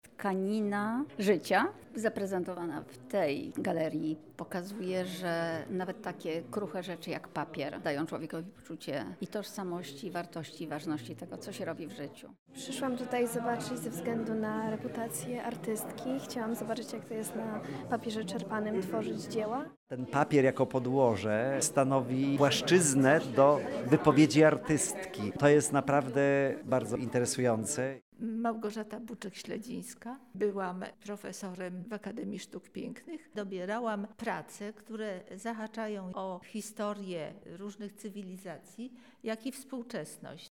Na wernisażu był również nasz reporter, który rozmawiał z artystką oraz przybyłymi gośćmi.